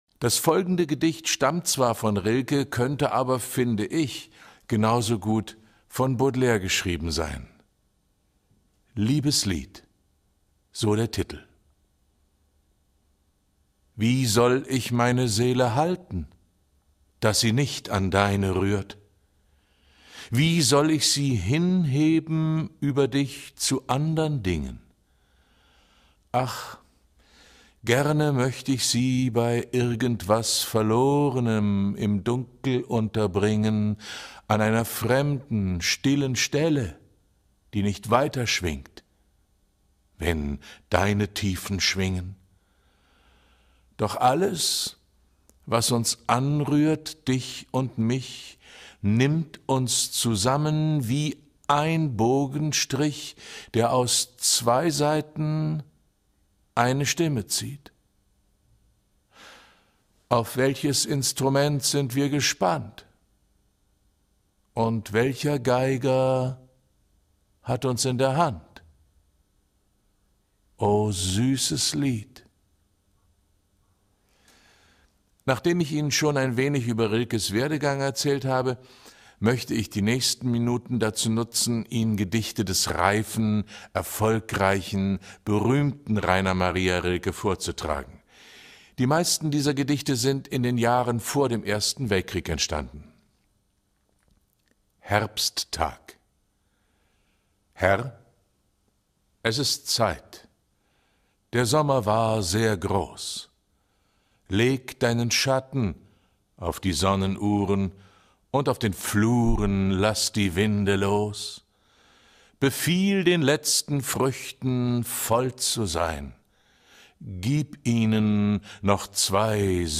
dazu befrage ich ihn in diesem Interview.